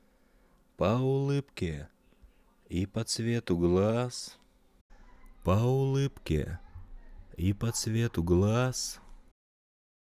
Конденсаторный Audix C112 ( на конденсаторном больше низа слышу), потом динамический Sm7b Вложения нив sm7 - audix c112 22.mp3 нив sm7 - audix c112 22.mp3 2,3 MB · Просмотры: 1.583 нив sm7 - audix c112.mp3 нив sm7 - audix c112.mp3 394,9 KB · Просмотры: 1.572